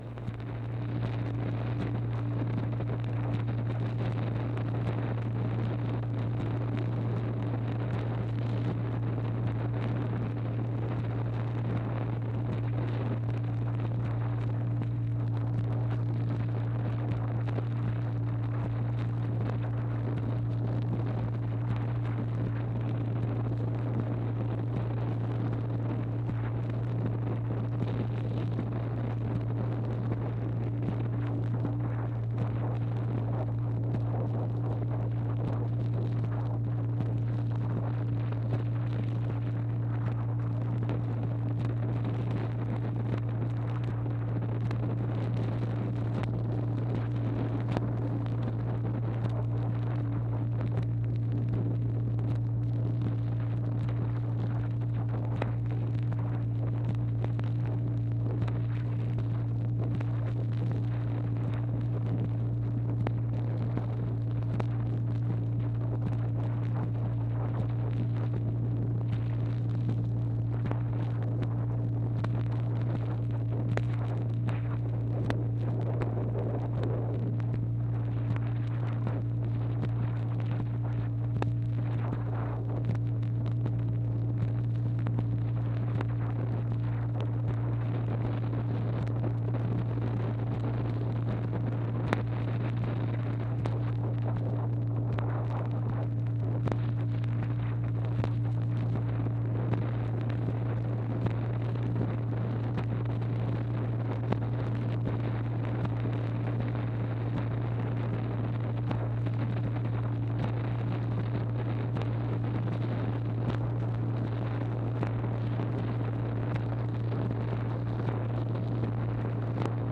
MACHINE NOISE, March 28, 1964
Secret White House Tapes | Lyndon B. Johnson Presidency